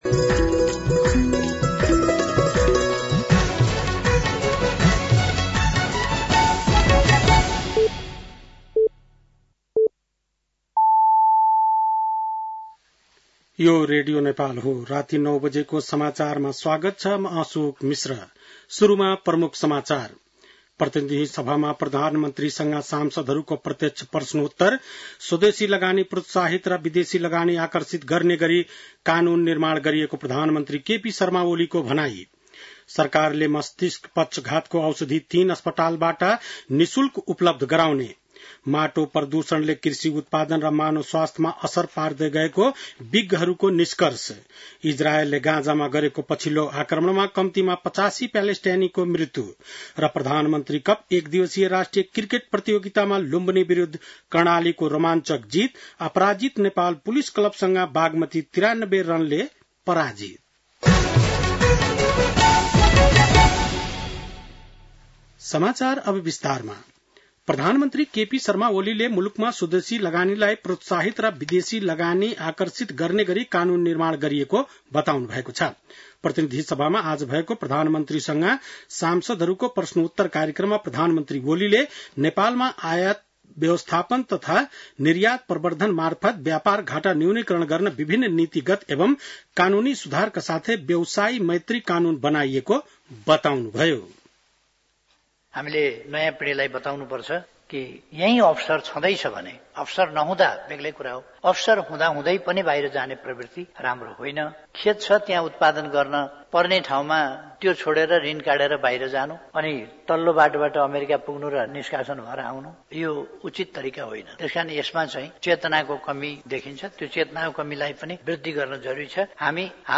बेलुकी ९ बजेको नेपाली समाचार : ७ चैत , २०८१